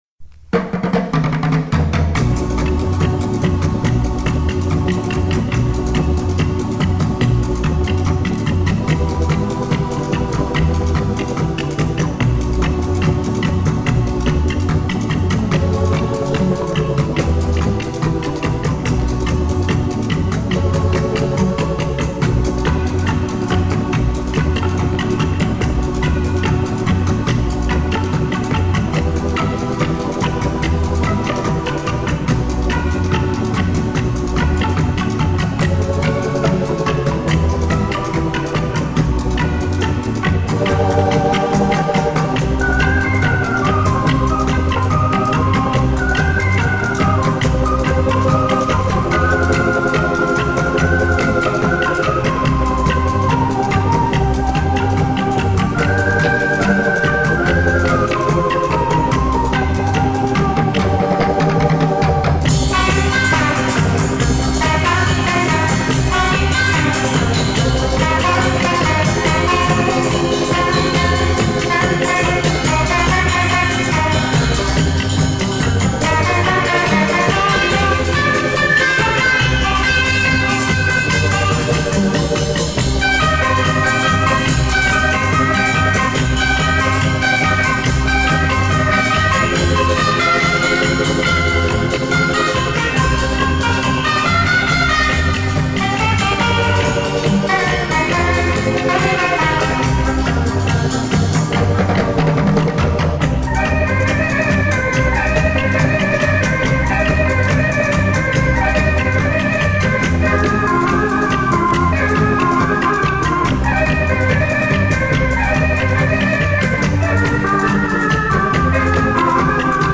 Обожаю surf-music.